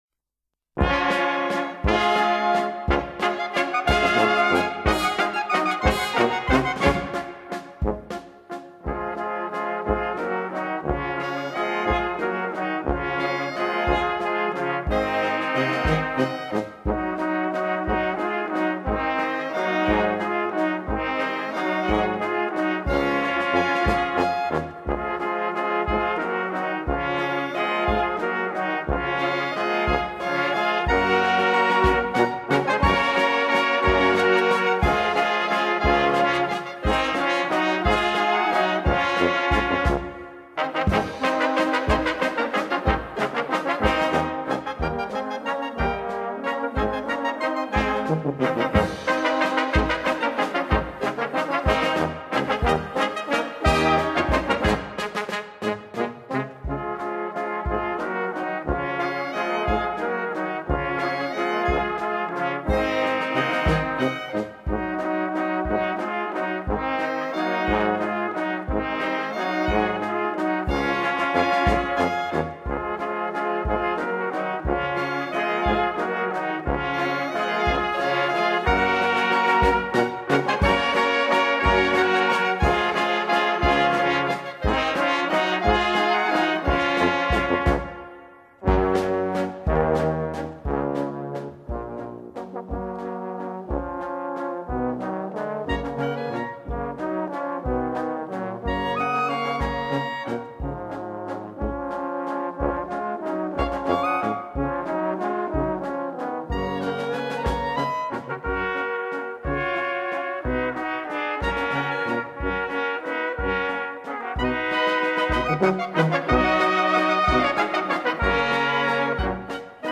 Walzer, Orchestertitel